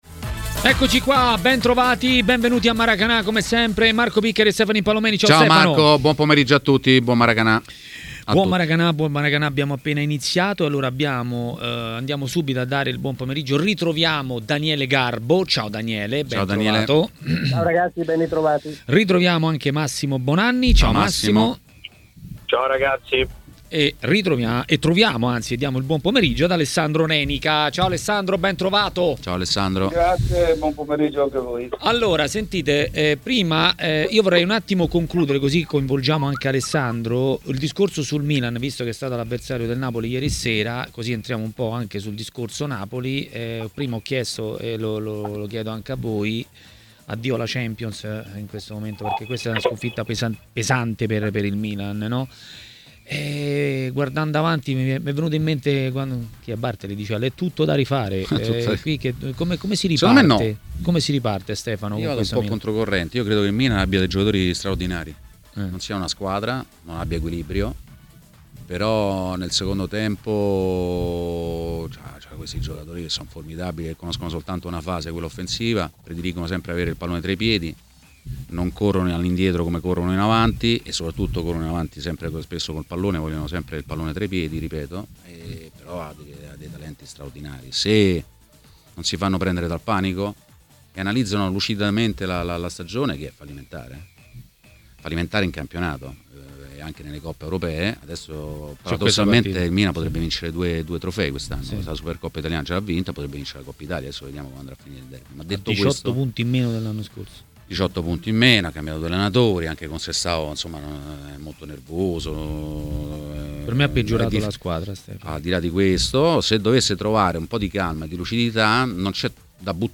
A parlare di Napoli e non solo a TMW Radio, durante Maracanà, è stato l'ex calciatore Alessandro Renica.